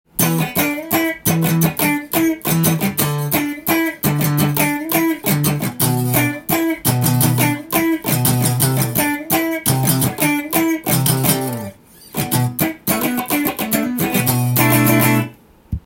センターピックアップにしてカッティング奏法で弾いてみました。
ジャキジャキ感が収まることを知りません。